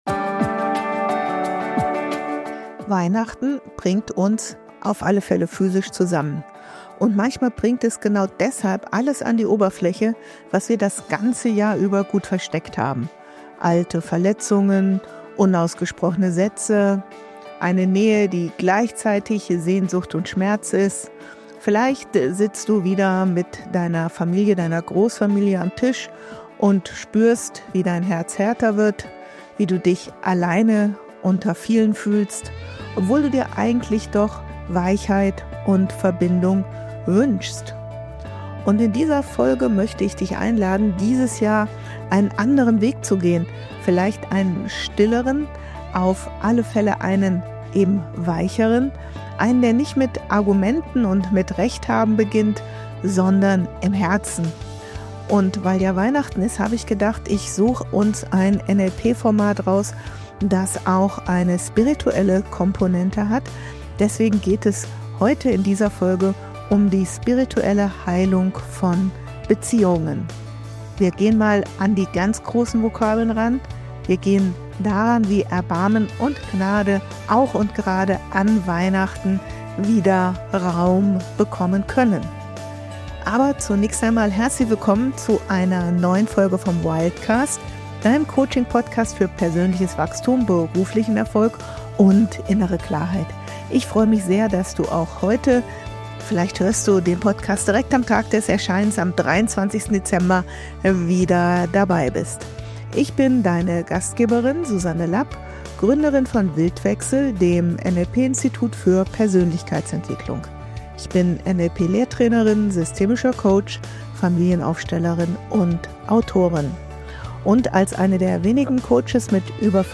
Herzstück dieser Folge ist eine geführte Meditation („Die Heilige Reise“ nach Robert Dilts) sowie ein spirituell erweitertes NLP-Coaching-Format auf Basis der Wahrnehmungspositionen 1-2-3, ergänzt um die Ressource der spirituellen Ganzheit.